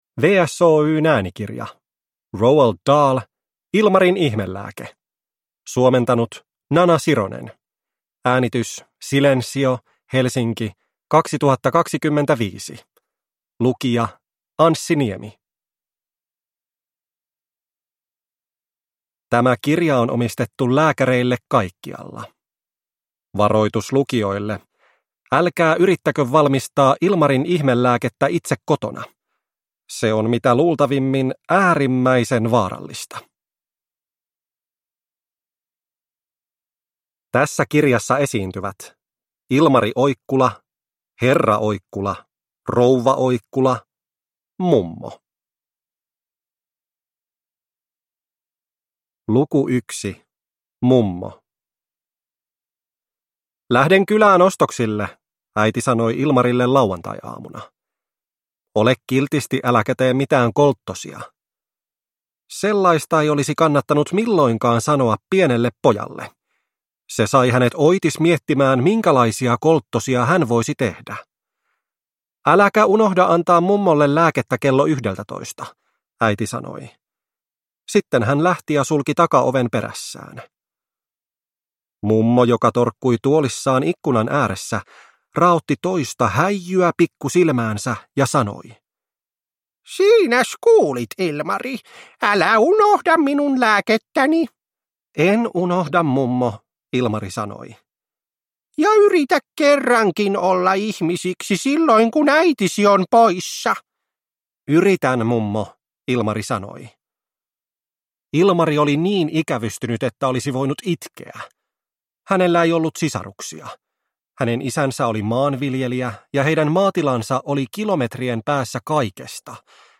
Ilmarin ihmelääke – Ljudbok